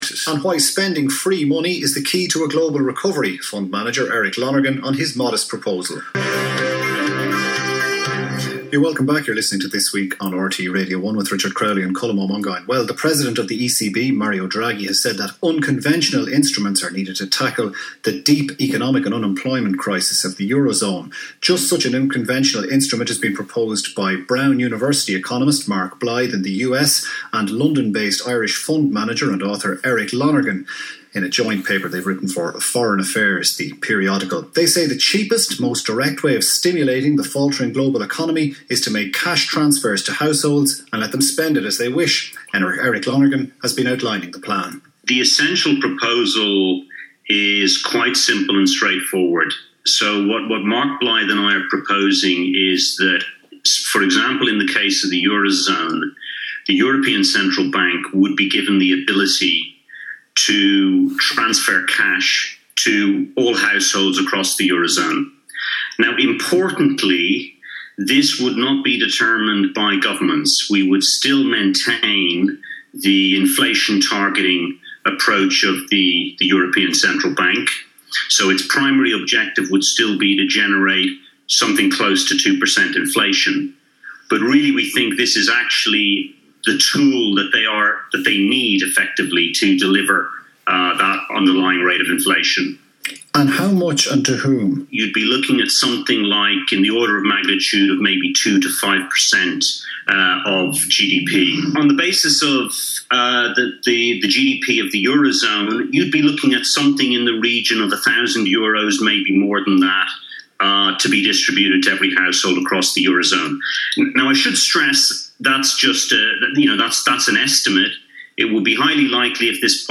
RTE interview
RTE-interview1.mp3